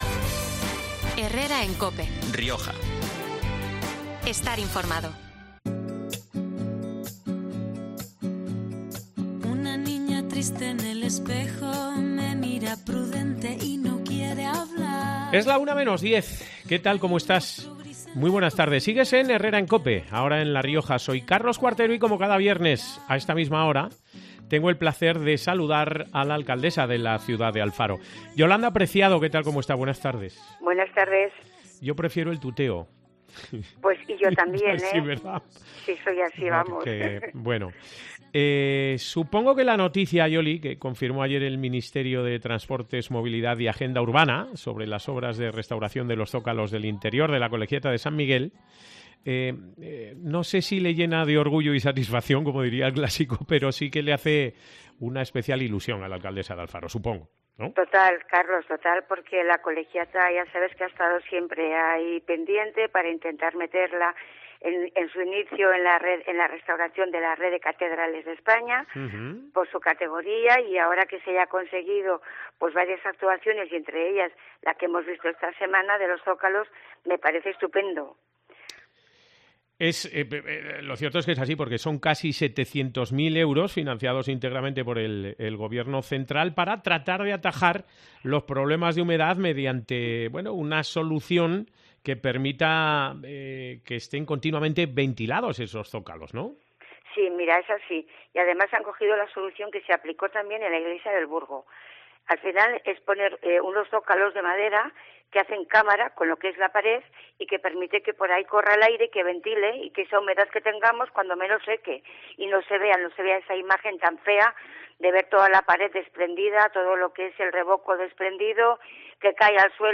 La alcaldesa alfareña, Yolanda Preciado, explicaba hoy en COPE Rioja que la solución adoptada por los técnicos para atajar los problemas de humedad que afectan al templo, es muy similar a que, en su día, se eligió para la iglesia del Burgo.